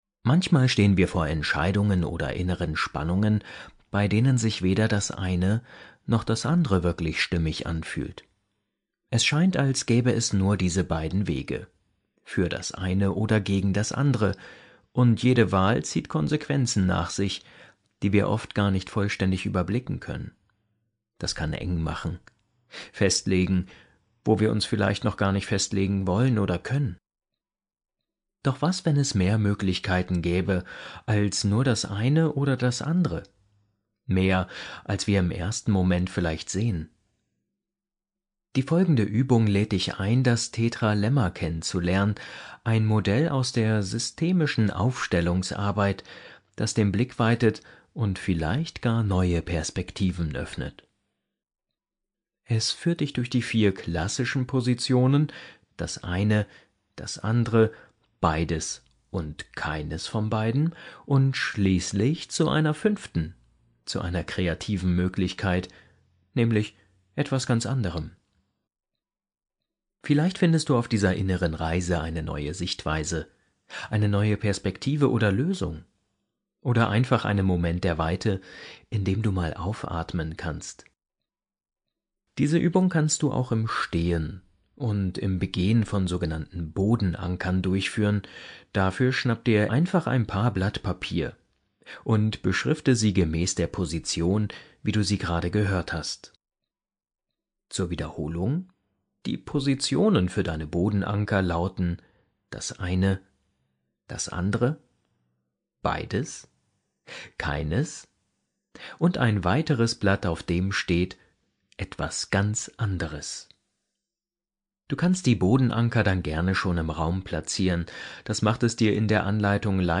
Mit dem Tetralemma unterstützt du schwierige Entscheidungsprozesse und kannst in dieser angeleiteten Übung Klarheit darüber gewinnen, welche Entscheidung sich für dich richtig und stimmig anfühlt.